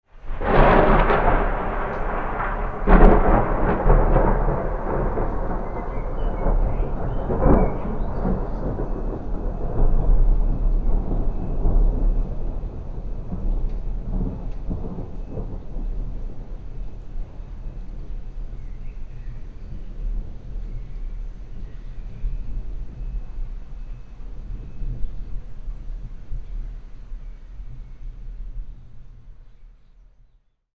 Lightning Strike And Thunder Sound Effect
Description: Lightning strike and thunder sound effect. Recreate the classic horror movie atmosphere with this Castle Thunder-style sound effect – dramatic lightning and deep, echoing thunder, perfect for Halloween, haunted houses, and spooky scenes.
Lightning-strike-and-thunder-sound-effect.mp3